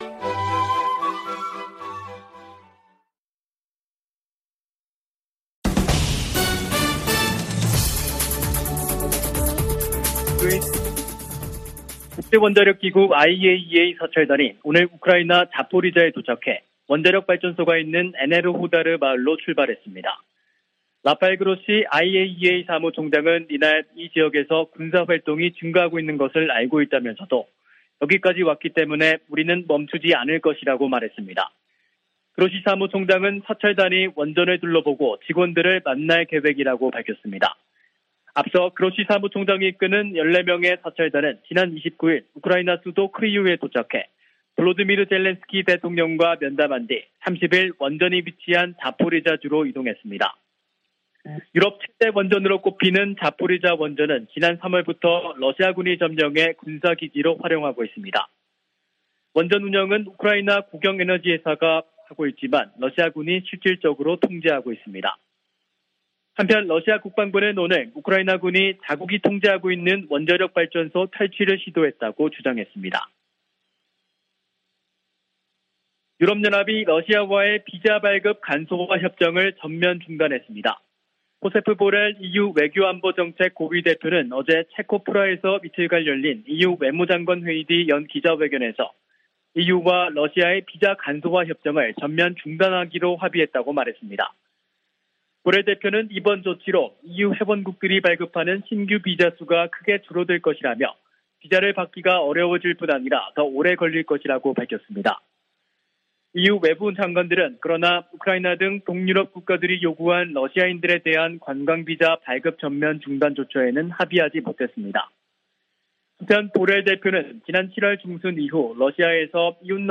VOA 한국어 간판 뉴스 프로그램 '뉴스 투데이', 2022년 9월 1일 2부 방송입니다. 한국에서 실시된 미한 연합군사연습, 을지프리덤실드(UFS)가 1일 끝났습니다. 미 국방부 부장관이 북한을 미국이 직면한 중대한 위협 가운데 하나로 지목하고, 도전에 맞서기 위한 기술 혁신이 필수적이라고 강조했습니다. 1일 하와이에서 열리는 미한일 안보수장 회동에서는 북한의 추가 도발 가능성에 대한 공조 방안이 집중 논의될 것이라고 미 전직 관리들은 전망했습니다.